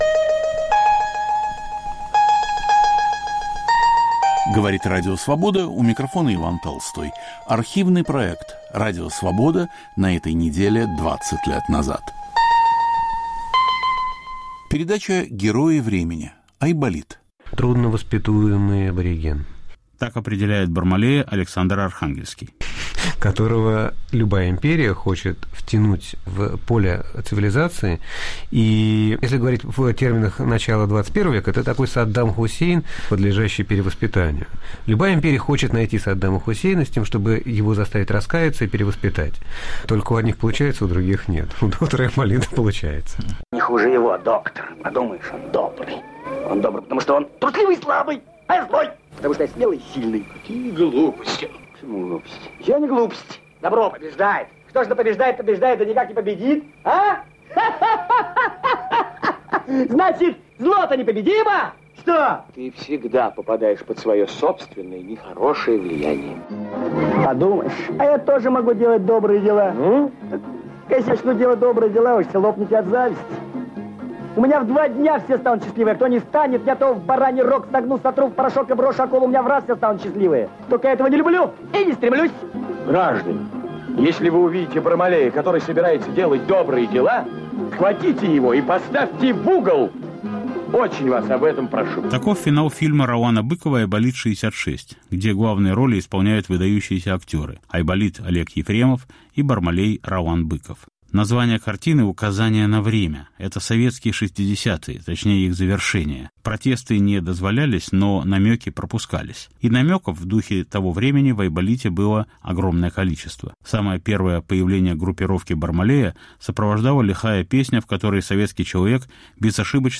Автор и ведущий Петр Вайль о Бармалее, Айболите и конце 1960-х в СССР. Фильм "Айболит-66".